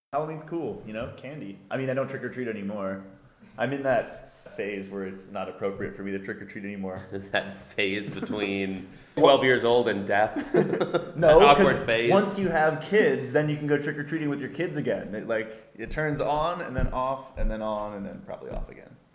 Schlagzeuger Daniel Platzman der Band „Imagine Dragons“ liebt diesen Feiertag vor allem wegen der Süßigkeiten, obwohl er selbst nicht mehr von Tür zu Tür zieht, verriet er im Interview: